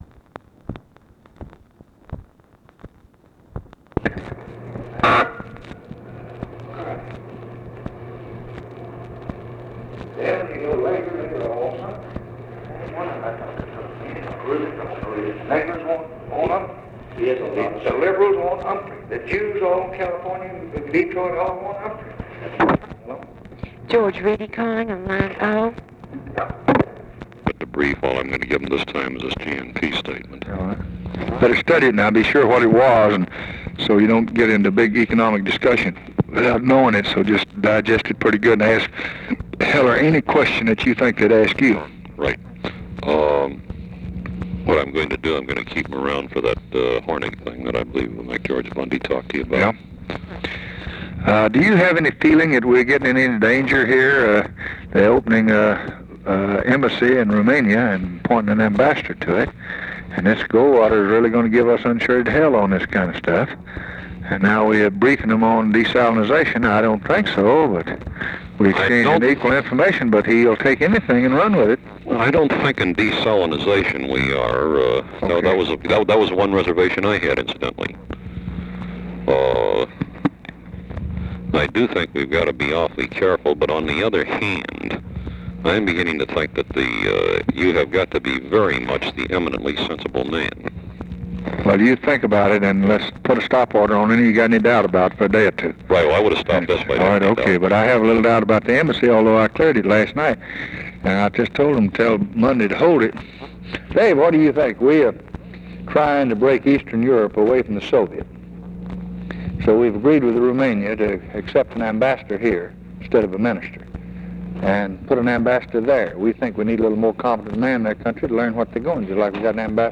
Conversation with GEORGE REEDY and OFFICE CONVERSATION, July 16, 1964
Secret White House Tapes